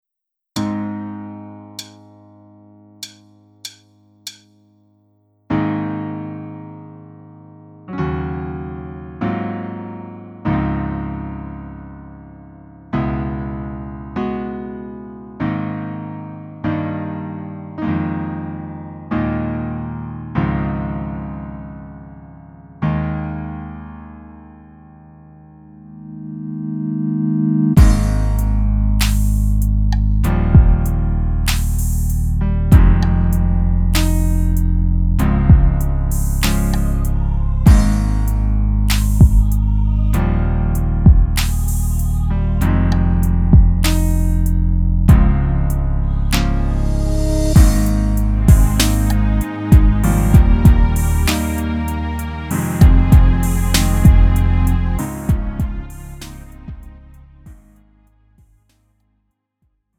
음정 -1키 3:27
장르 가요 구분 Lite MR